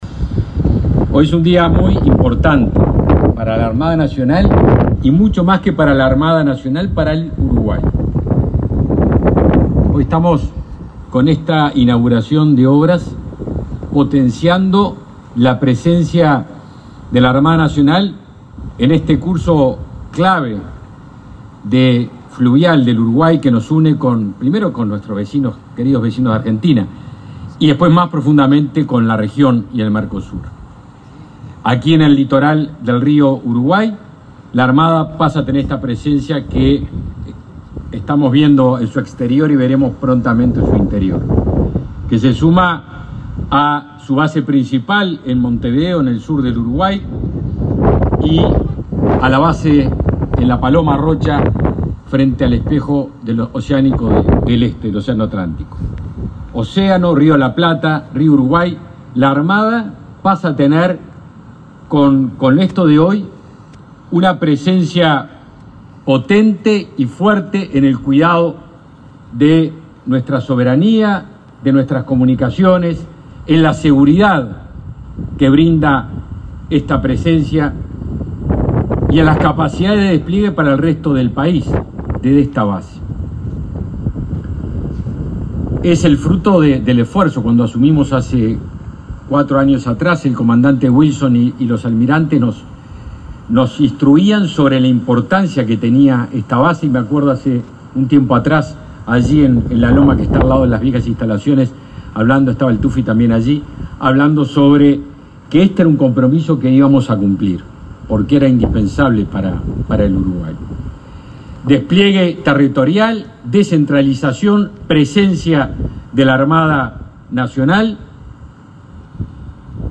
Palabras del ministro de Defensa Nacional, Javier García
El ministro de Defensa Nacional, Javier García, participó de la inauguración de las instalaciones de la base naval Capitán Cuerpo de Prefectura Luis